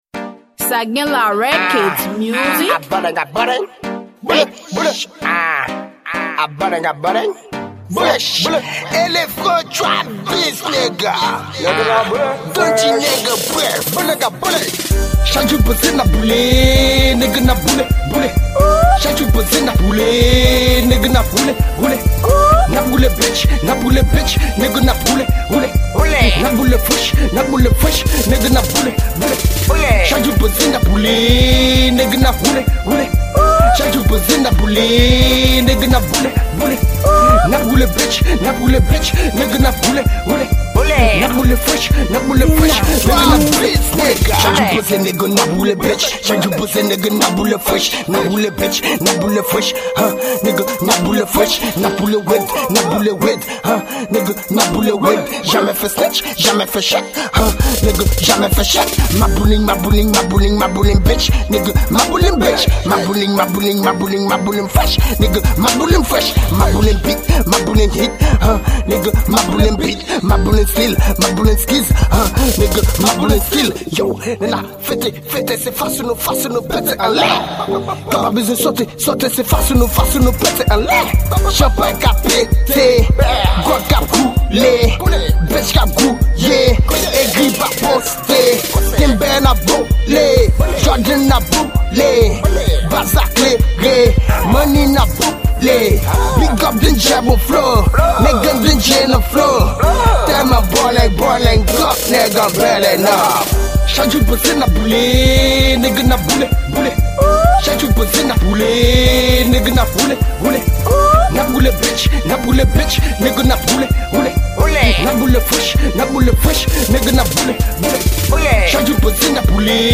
Genre: TraP